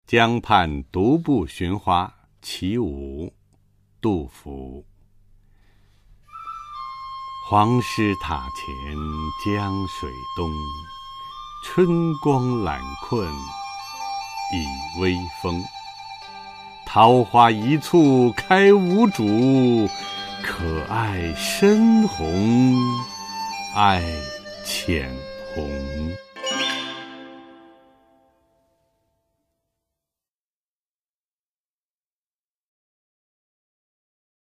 [隋唐诗词诵读]杜甫-江畔独步寻花（男） 唐诗朗诵